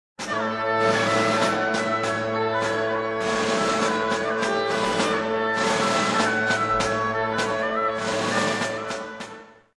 Konzertstück